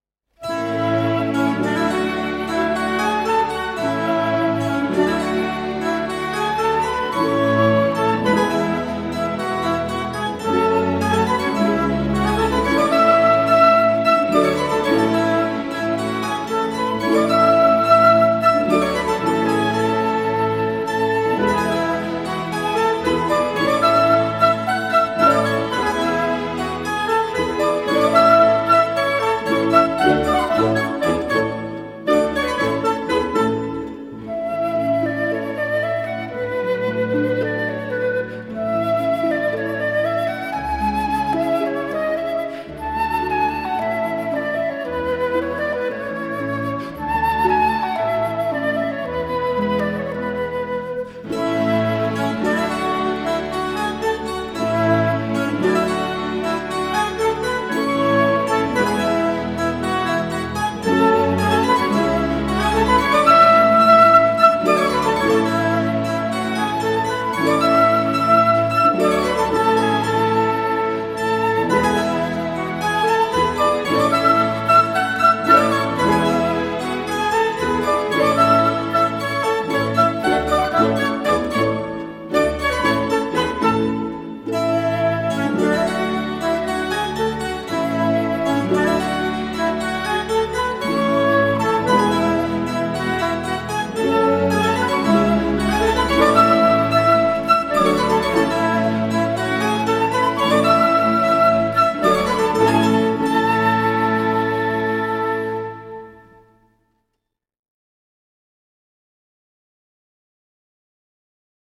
piano, clavecin, flûte, violoncelle, accordéon…